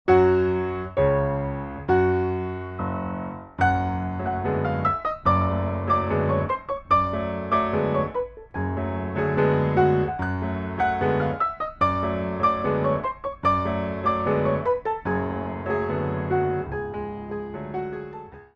2/4 - 32 with repeat
4 Count introduction included for all selections